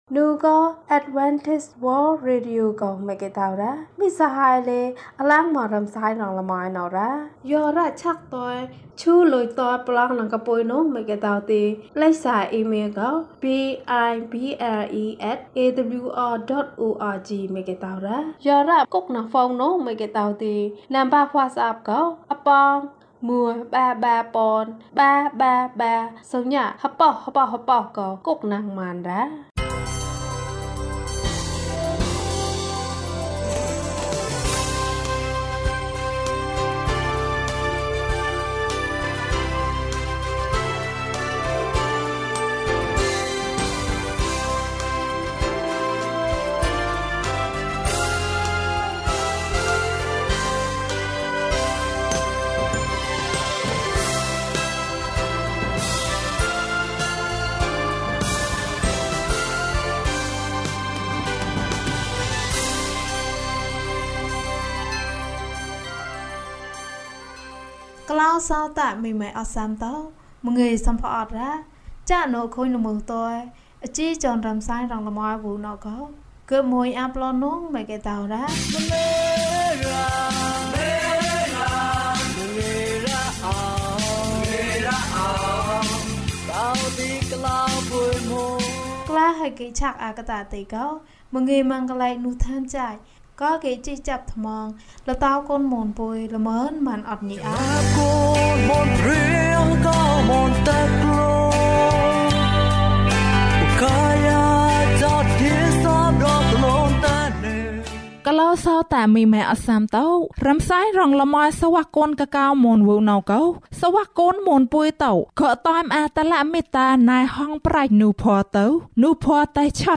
အံ့သြဖွယ်အချစ်။ ကျန်းမာခြင်းအကြောင်းအရာ။ ဓမ္မသီချင်း။ တရားဒေသနာ။